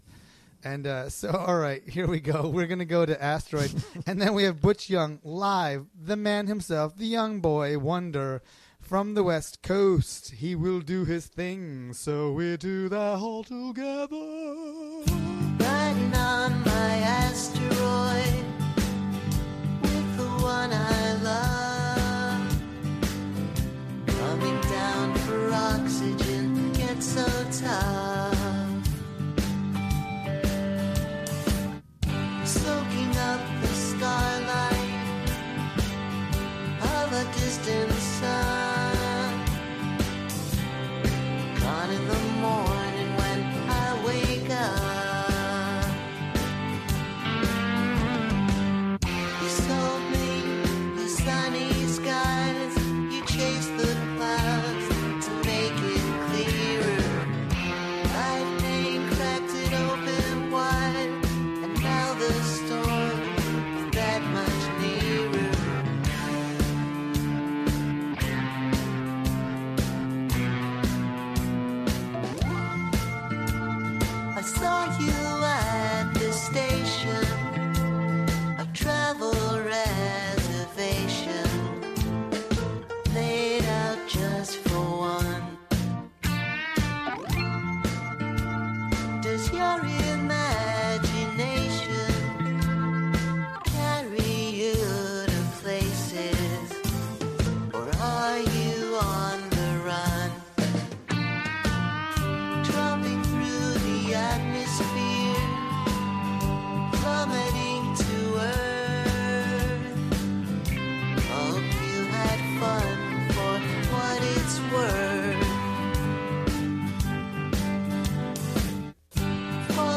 spoke via telephone from Los Angeles during the WGXC Afternoon Show